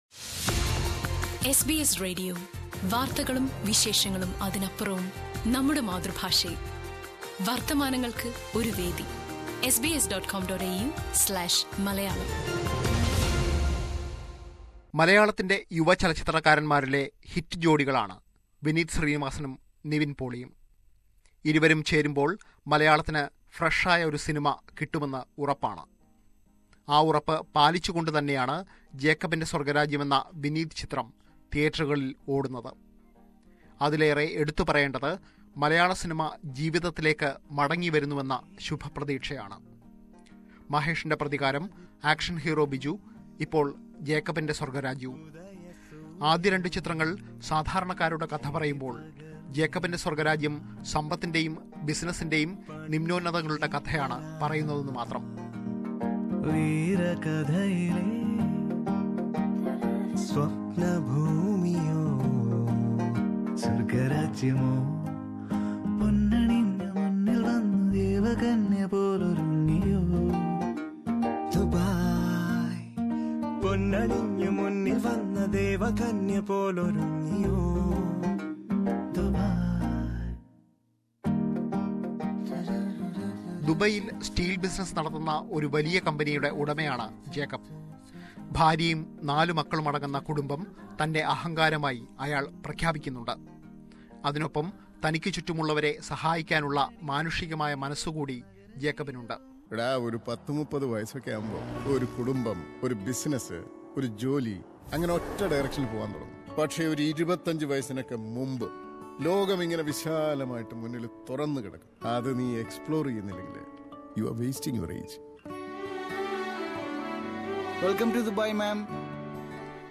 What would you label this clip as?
Review: Jacobinte Swargarajyam